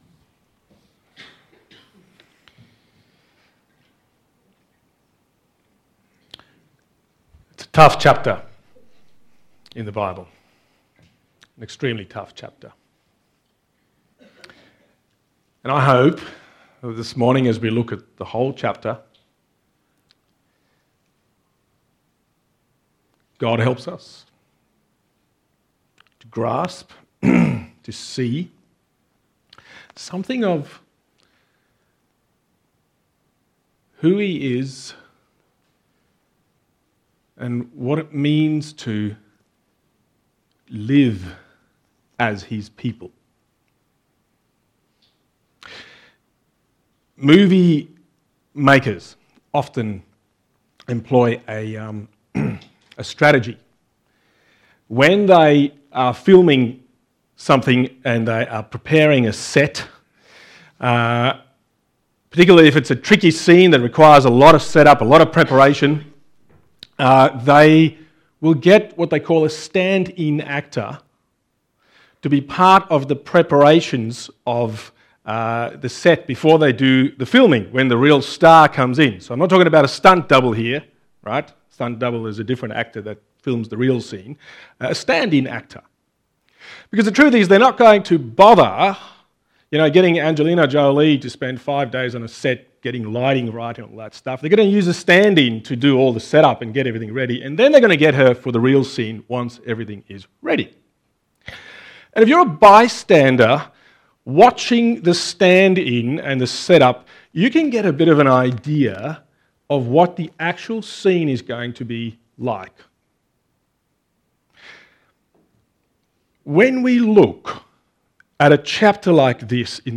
Text: Exodus 32: 1-35 Sermon